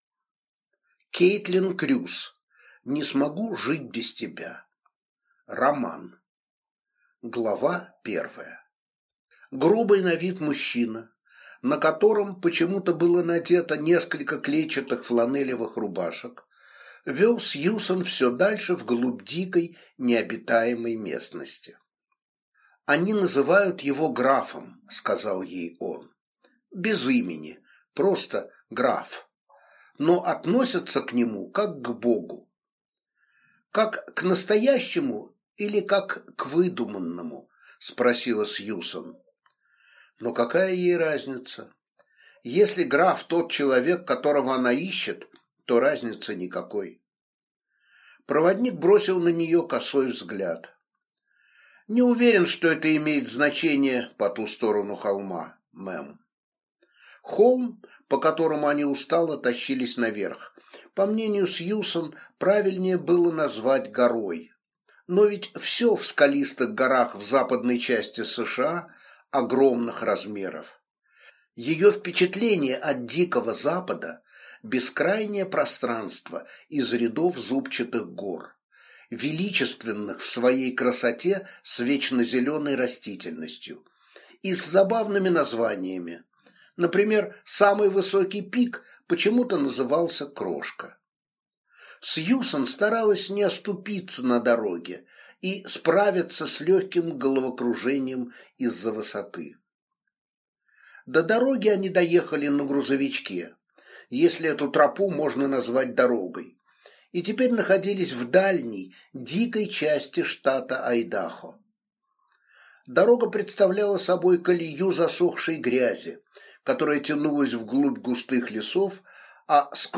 Аудиокнига Не смогу жить без тебя | Библиотека аудиокниг